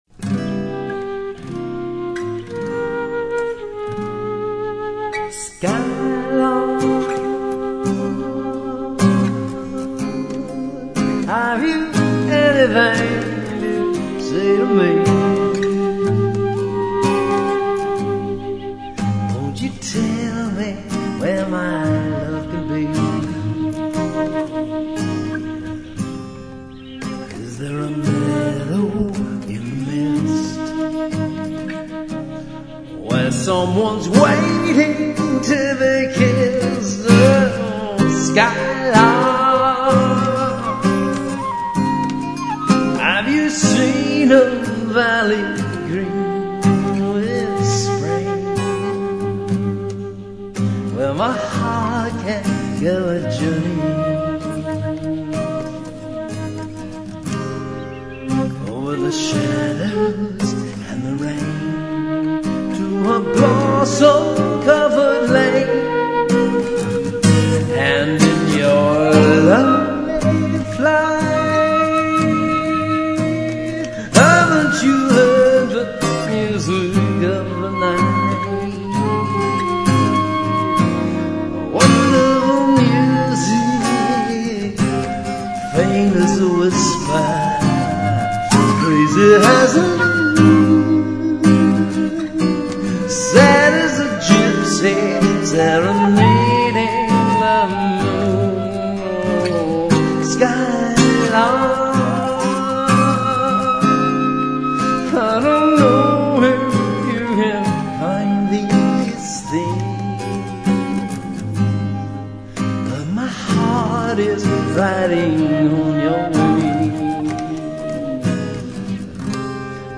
guitar, vocal
flute